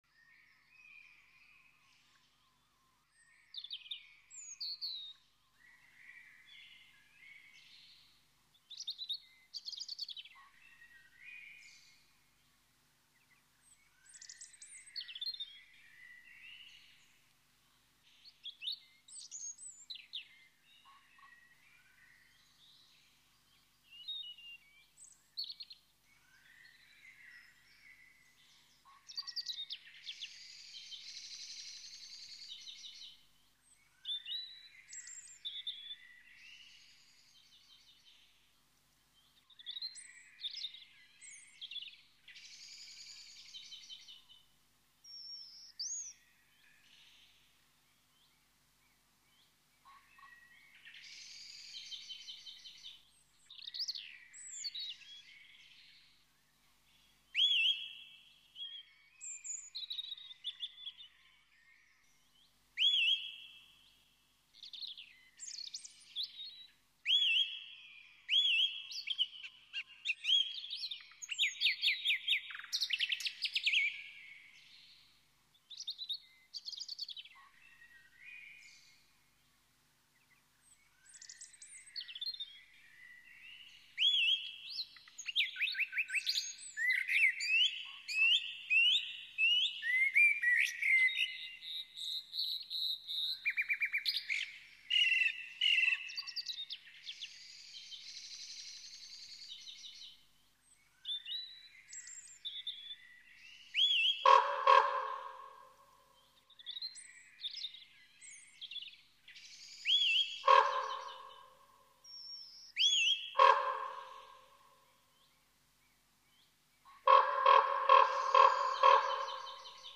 Donderdag 5 mei t/m zondag 8 mei 2011 Kapel Franciscanessen Bisschop Zwijsenstraat tussen nummer 20 en 24 Tilburg ‘Suskewiet’ is de zang van een vink, ‘Suskewiet’ is ook de naam van een expositie rondom vogels.
(klik op affiche voor geluiden tentoonstelling)
suskewiet-stereo.mp3